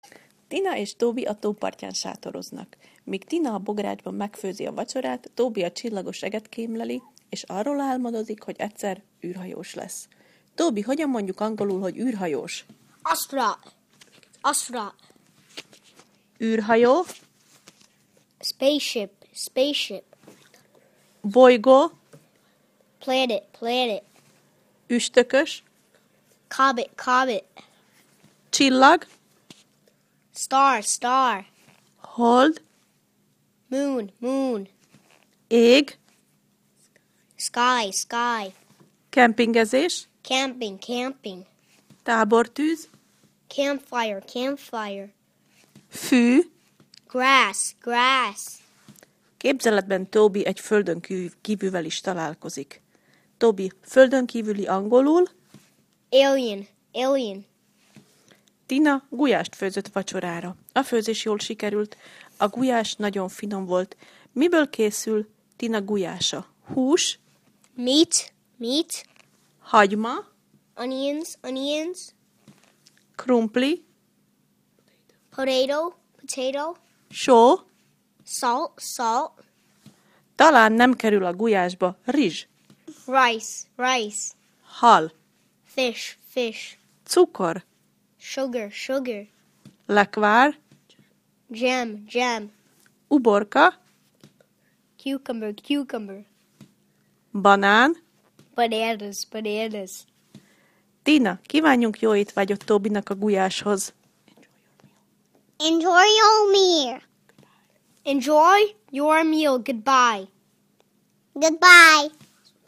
Az ebben a leckében szereplő szavak helyes kiejtését meghallgathatod Tobytól.